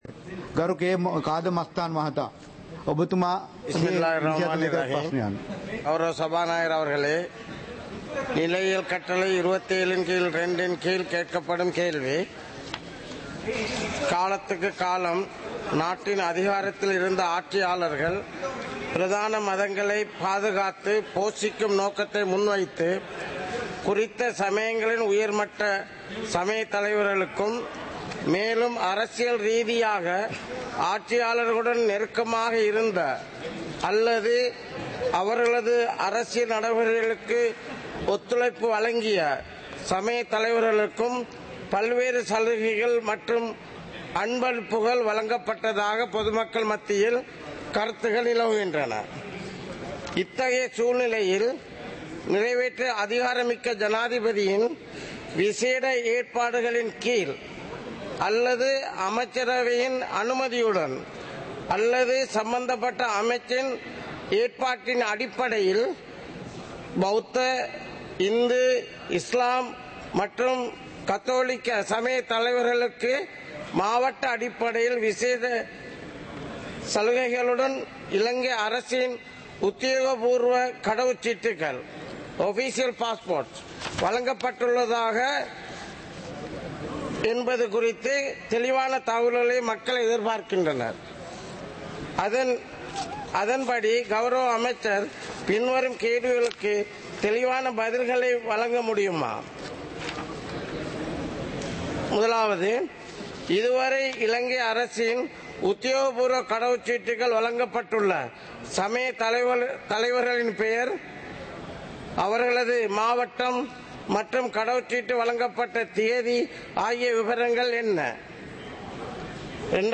நேரலை - பதிவுருத்தப்பட்ட